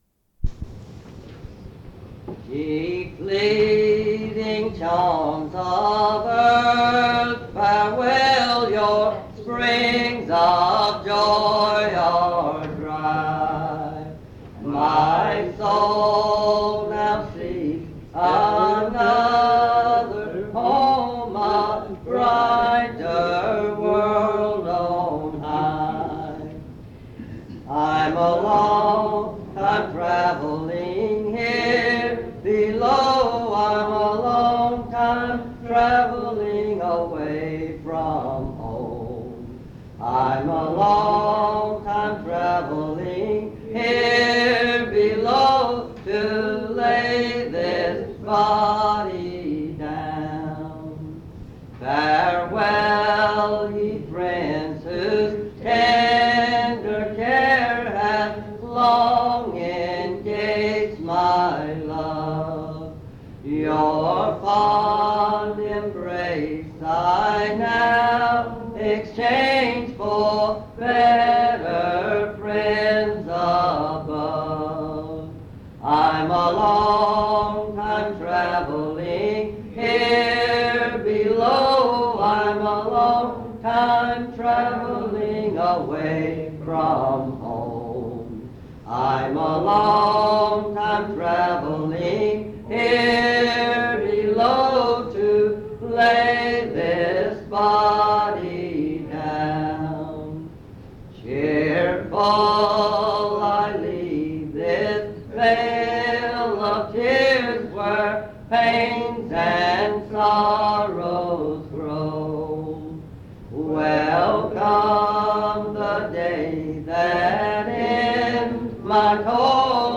Message
at Monticello Primitive Baptist Church